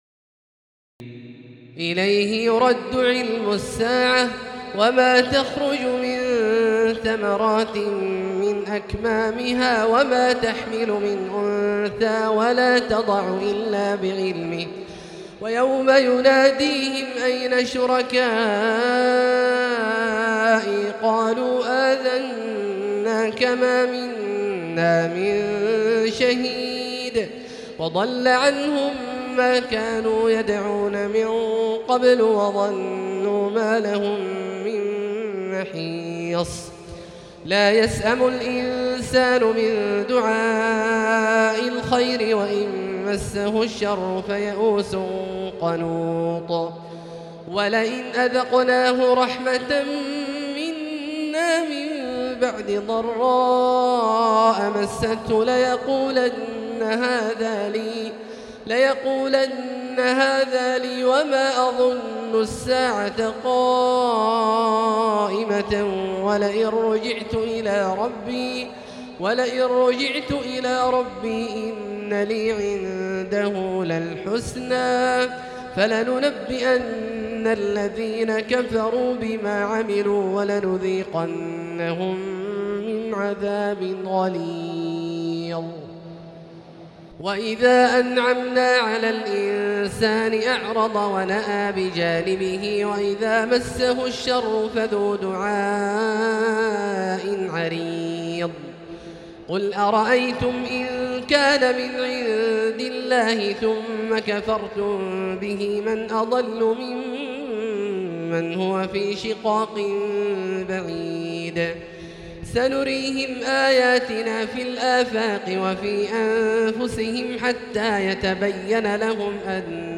تراويح ليلة 24 رمضان 1438هـ من سور فصلت (47-54) و الشورى و الزخرف (1-25) Taraweeh 24 st night Ramadan 1438H from Surah Fussilat and Ash-Shura and Az-Zukhruf > تراويح الحرم المكي عام 1438 🕋 > التراويح - تلاوات الحرمين